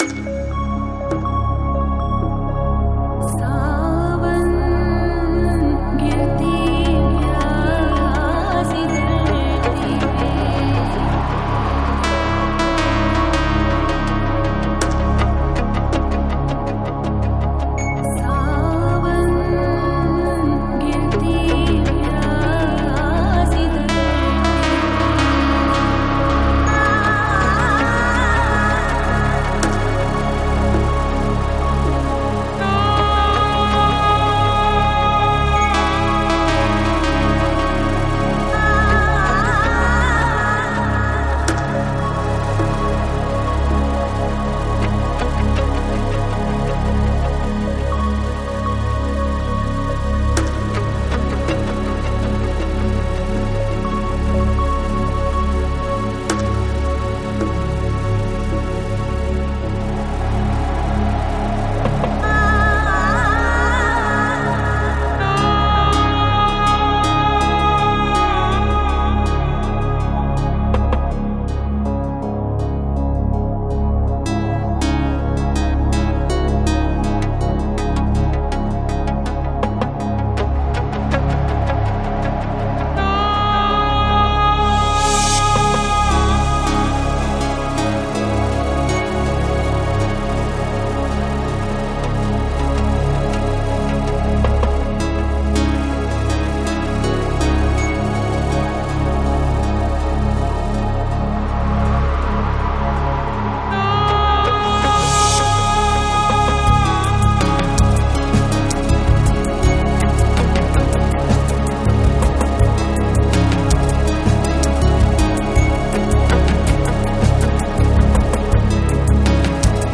Жанр: Ambient
22:27 Альбом: Psy-Trance Скачать 9.05 Мб 0 0 0